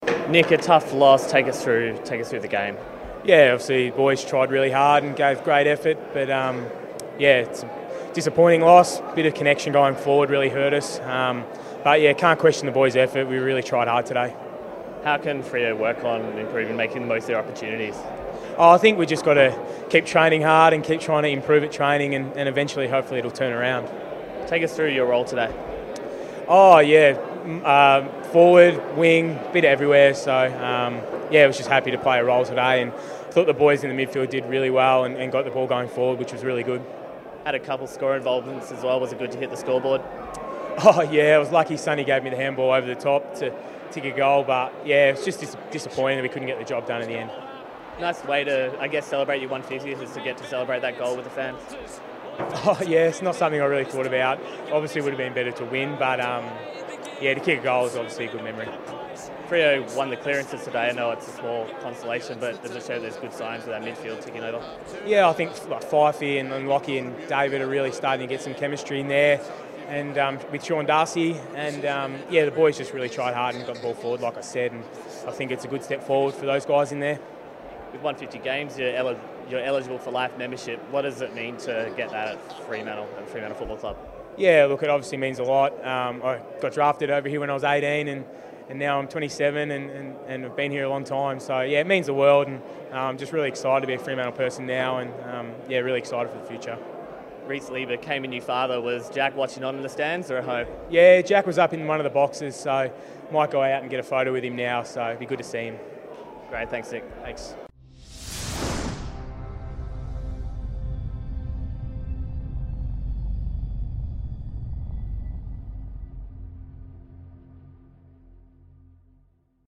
Nick Suban chats to Docker TV after Freo's clash against the Eagles.